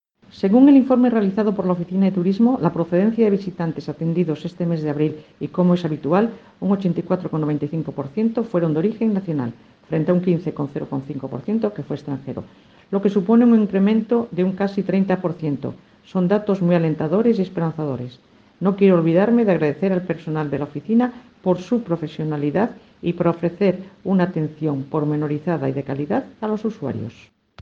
Audio de la alcaldesa, María Teresa Noceda Llano